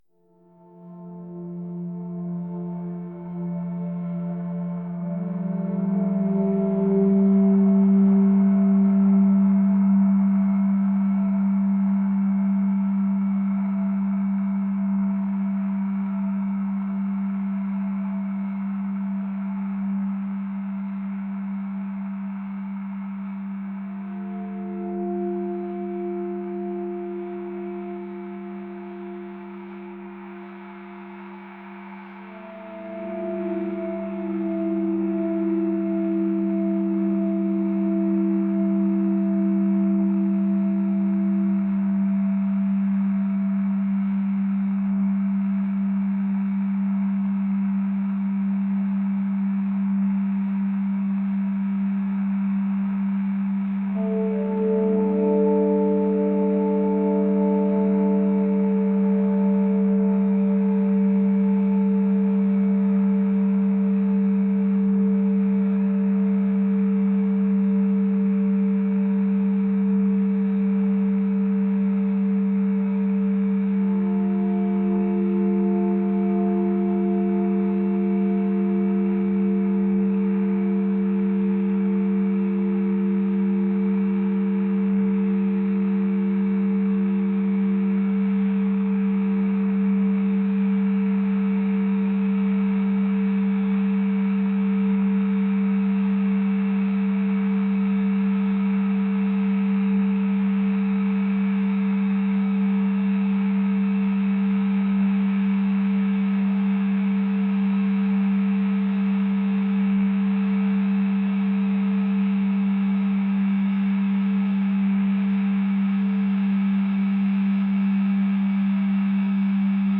ethereal | ambient | atmospheric